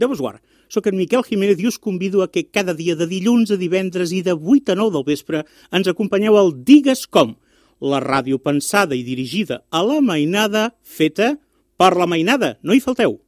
Promoció